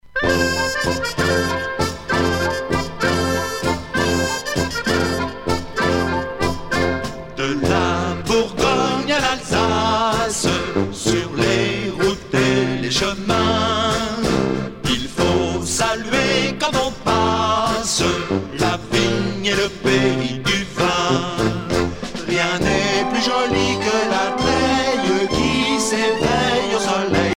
danse : bourree
Pièce musicale éditée